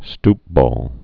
(stpbôl)